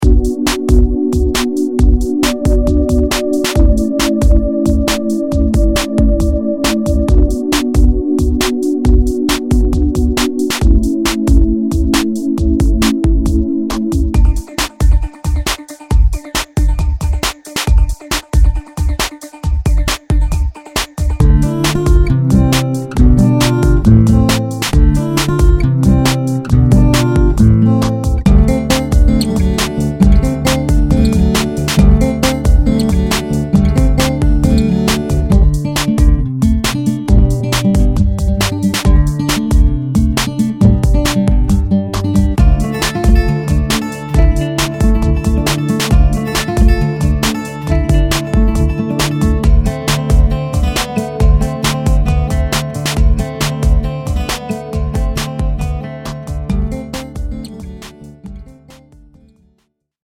そのため（？）今回も遠慮なく、既成のループを足し合わせて、楽曲もどきを作りました。
ほとんどの楽曲で演奏はしていません。ただ組み合わせているだけです。